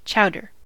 chowder: Wikimedia Commons US English Pronunciations
En-us-chowder.WAV